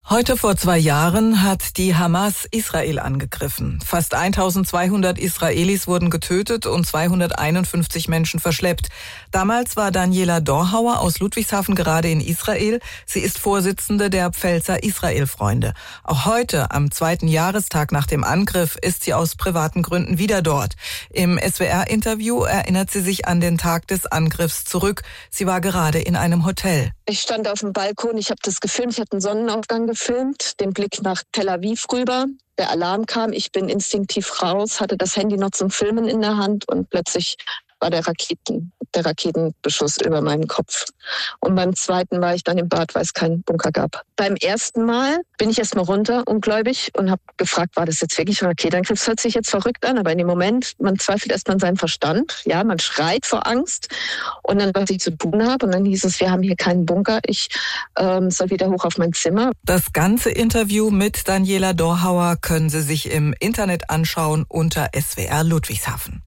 Während des Gesprächs gab es in Israel immer wieder Luftalarm.